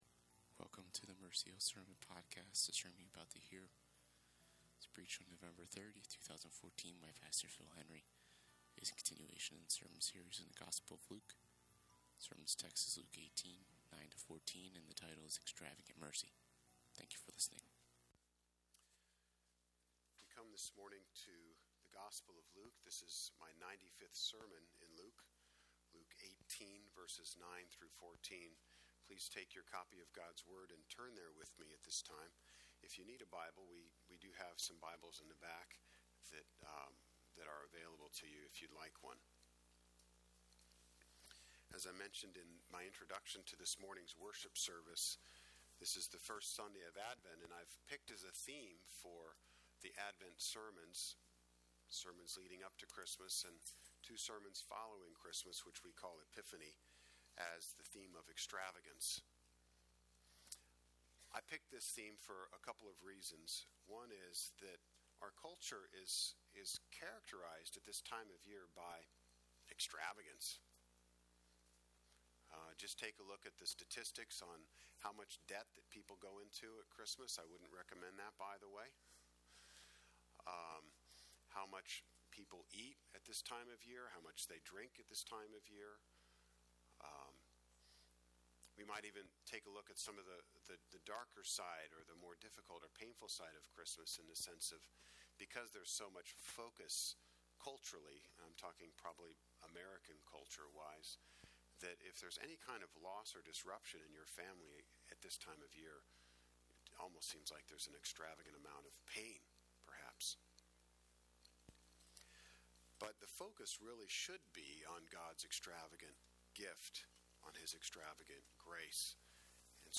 Mercy Hill Presbyterian Sermons - Mercy Hill NJ